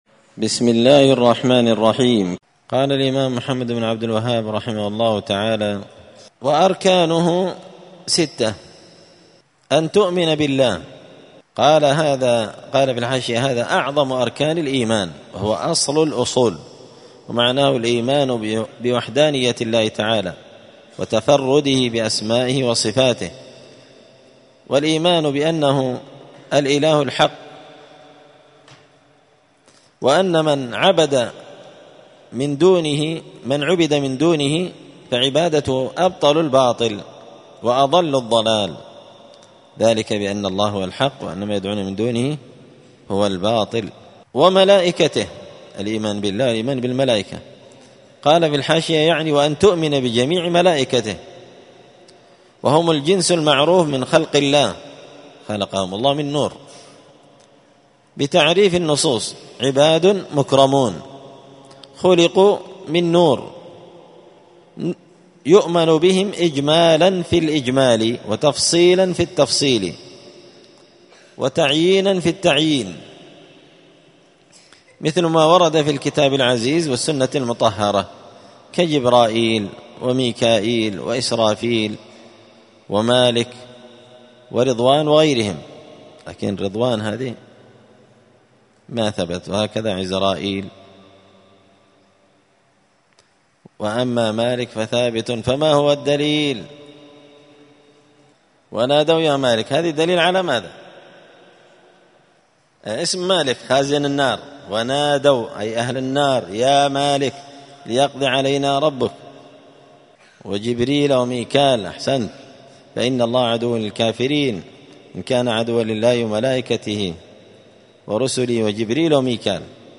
دار الحديث السلفية بمسجد الفرقان بقشن المهرة اليمن
*الدرس السابع والعشرون (27) من قوله {وأركان الإيمان ستة…}*